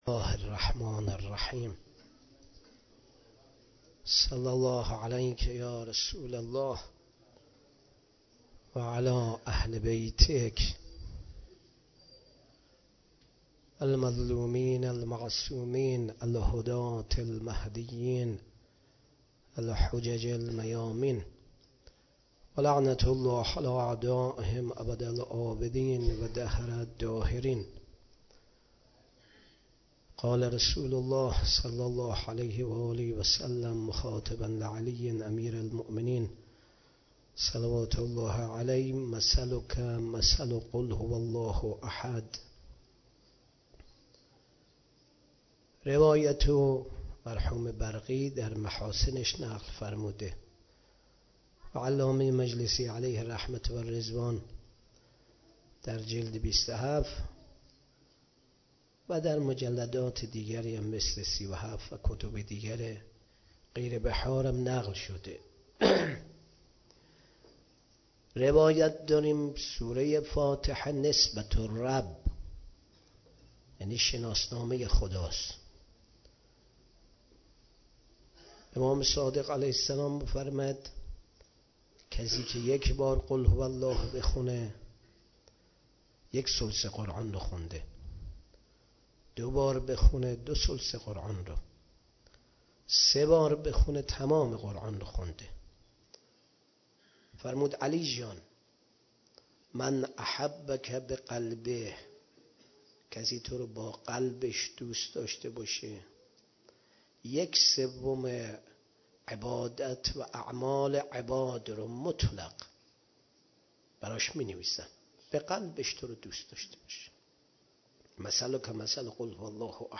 18 شهریور 96 - غمخانه بی بی شهربانو - سخنرانی